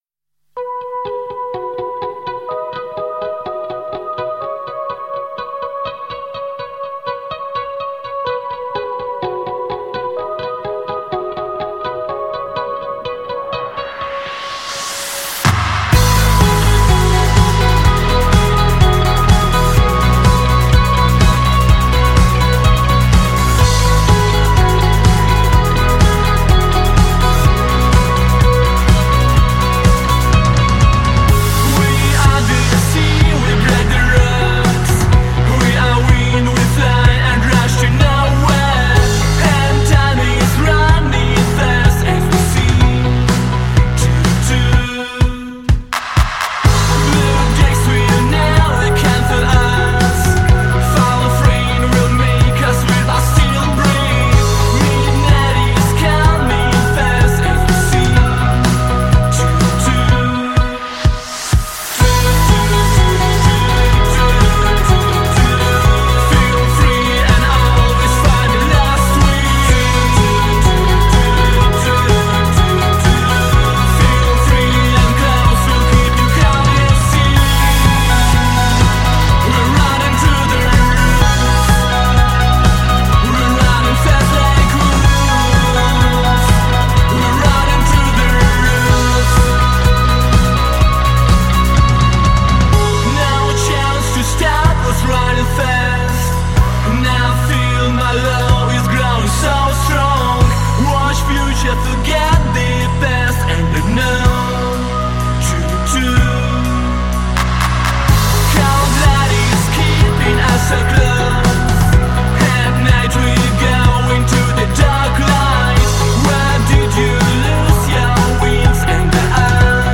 four piece indie pop band